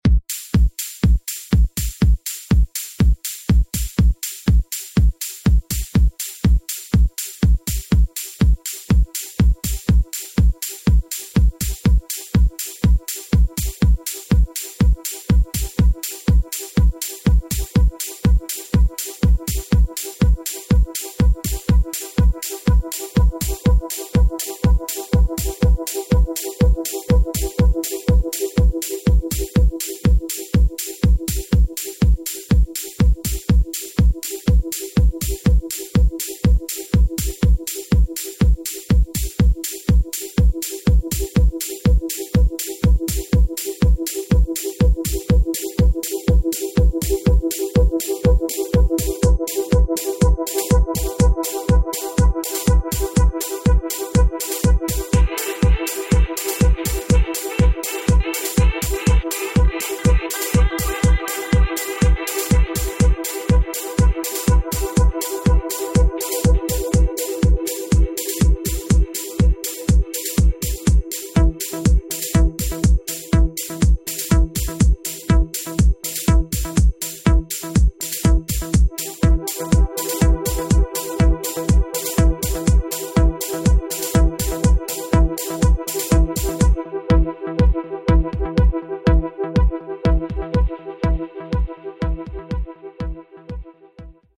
easily the most soothing of the bunch.
with classic 90s chords and accompanying bass and horns.
This record has it all for lovers of the 90s house sound!